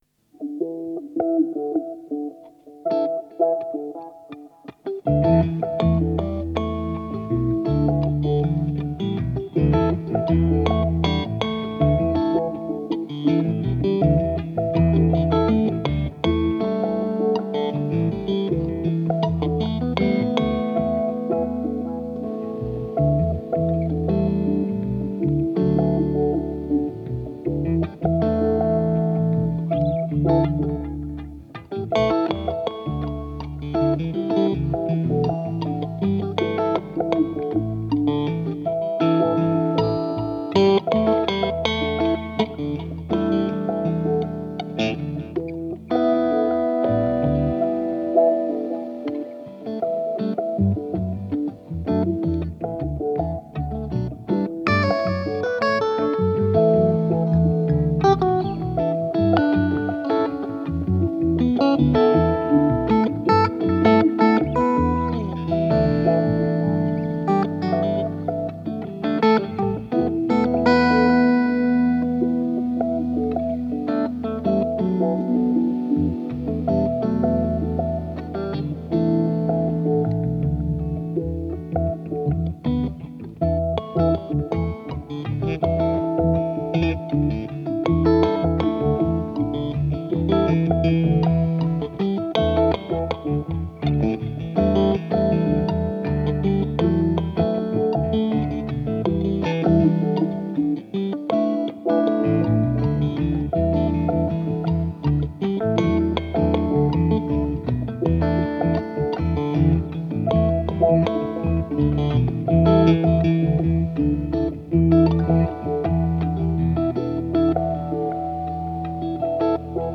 Gefällt mir, das hört sich ein bisschen wie eine Maultrommel an, dieses Ping Pong der Gitarrenklänge, das hallt schön nach.